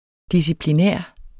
Udtale [ disipliˈnεˀɐ̯ ]